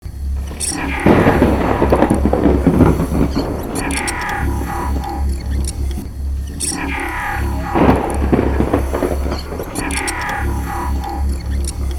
portal_idle.wav